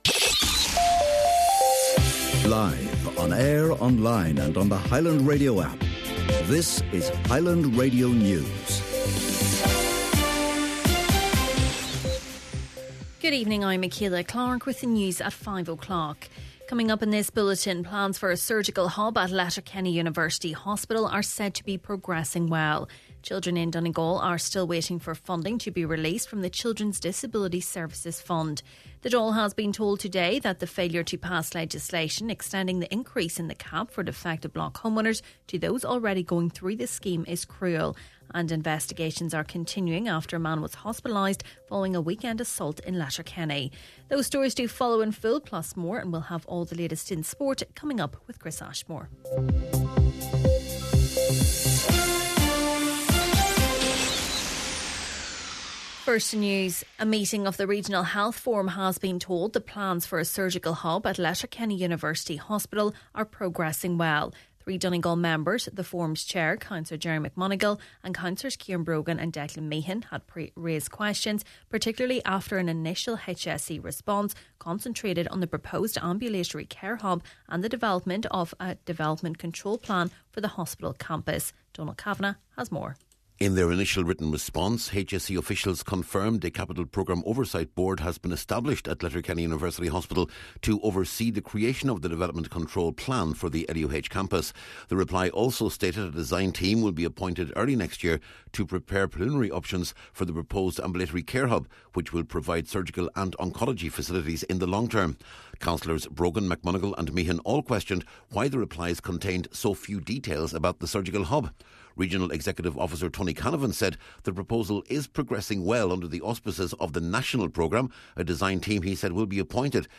Main Evening News, Sport and Obituaries – Tuesday, September 23rd